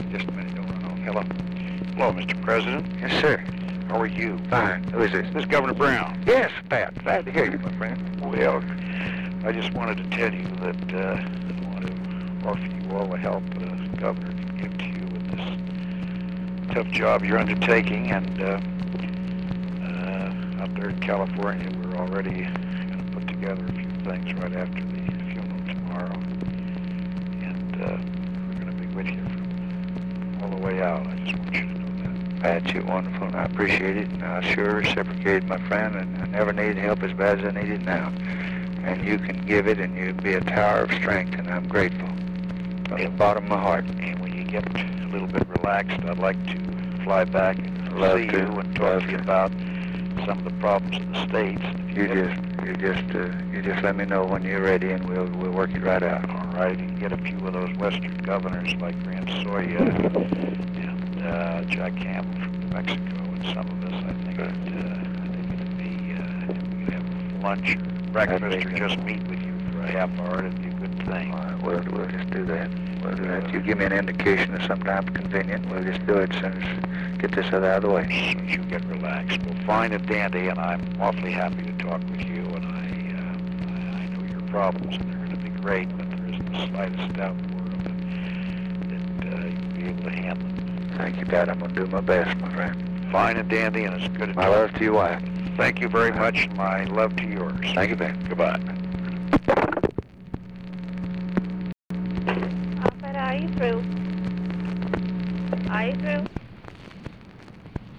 Conversation with EDMUND "PAT" BROWN, November 24, 1963
Secret White House Tapes